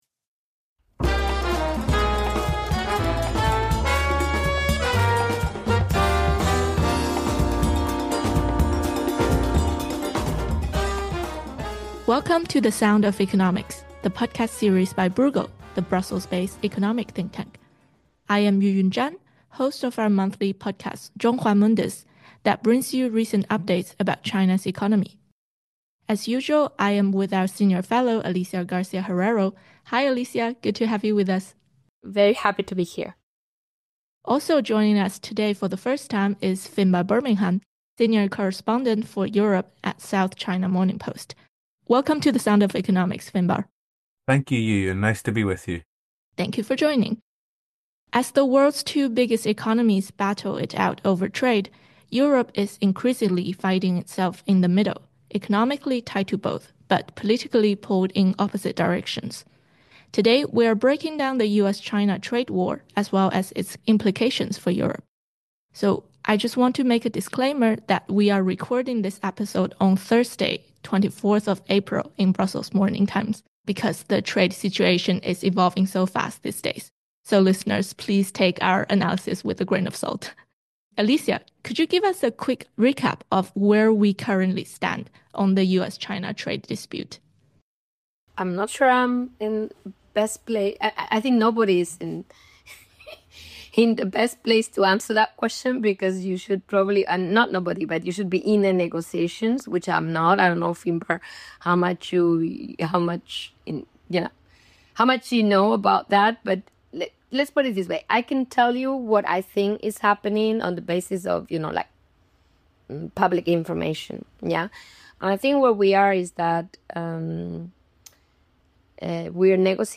With Trump’s volatile approach to tariffs, China’s charm offensive toward Brussels, and the EU’s own strategic uncertainty, the conversation dives deep into the geopolitical triangulation reshaping global trade order.